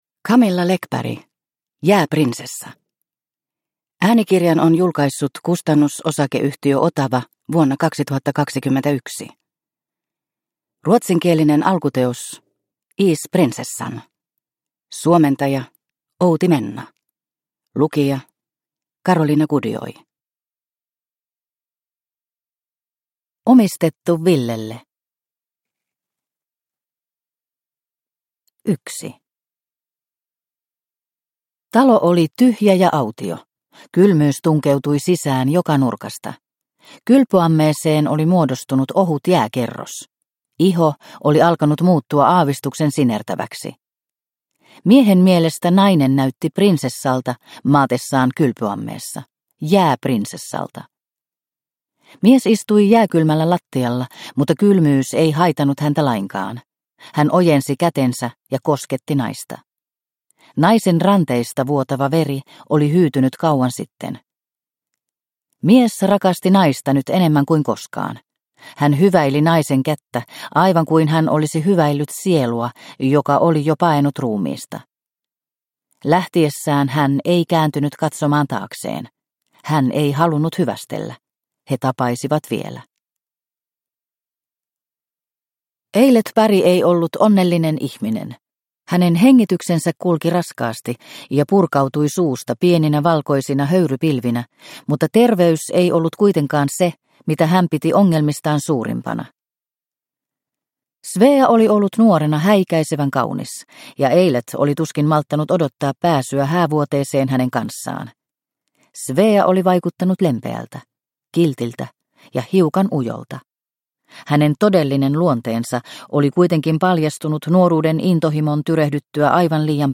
Jääprinsessa – Ljudbok – Laddas ner